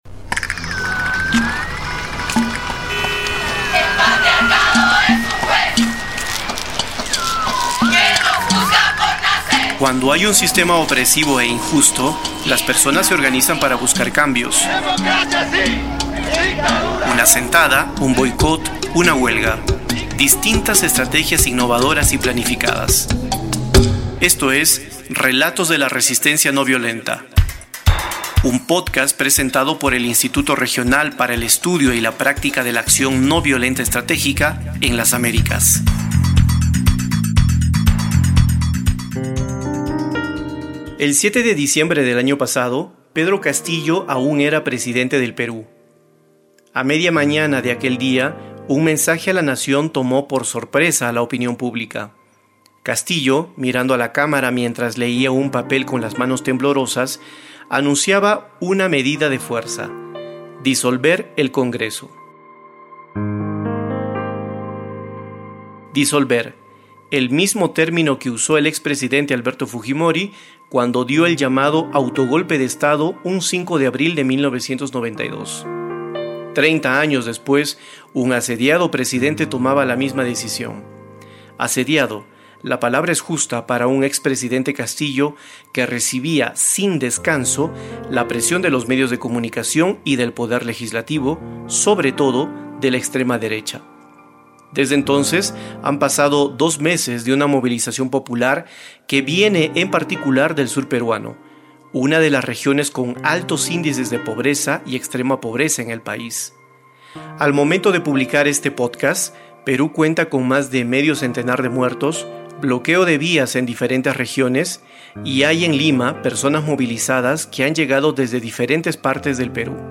Tamaño: 23.37Mb Formato: Basic Audio Descripción: Entrevista - Acción ...